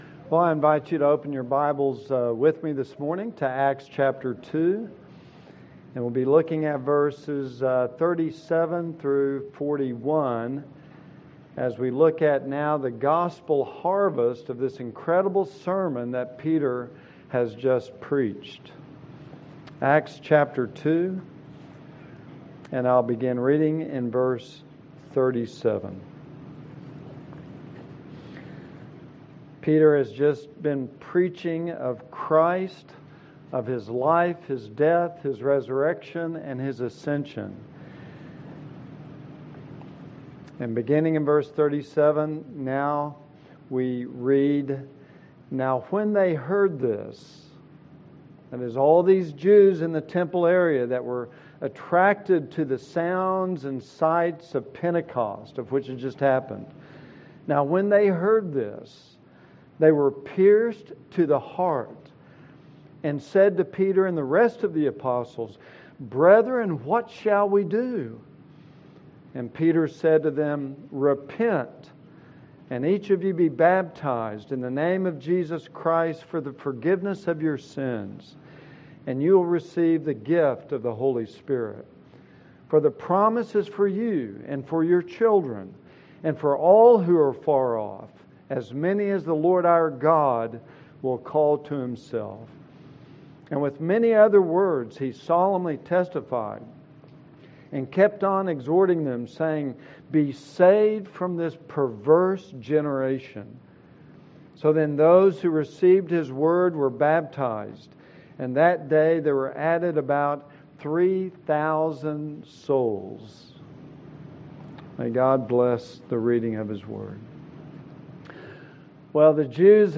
James 1:19-21 Service Type: Sunday School